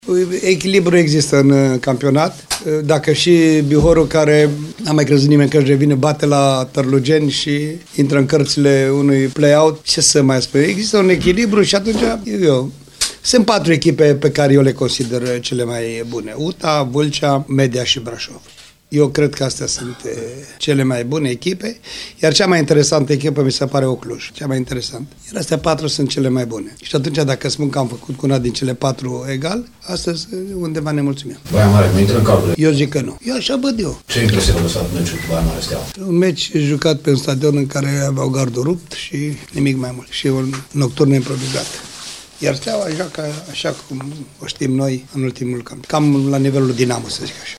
Apoi ambii antrenori şi-au mai expus punctele de vedere despre B2-ul fotbalistic de la noi: